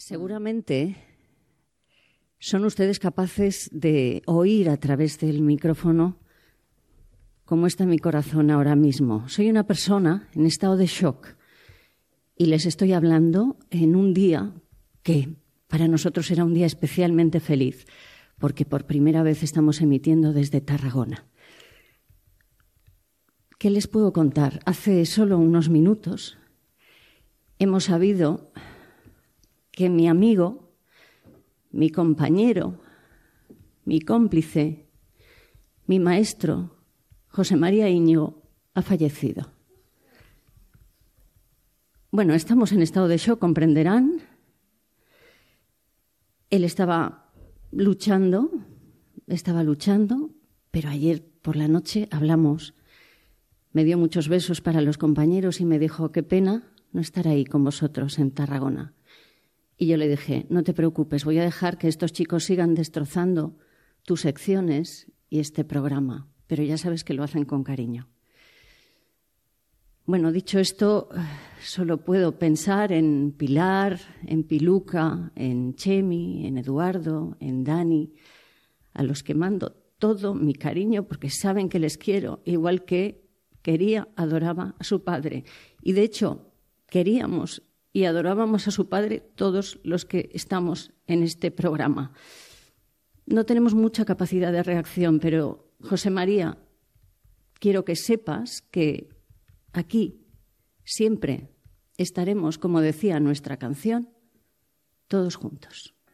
Programa, fet des de Tarragona, el dia després de la mort del periodista i col·laborador del programa José María Íñigo.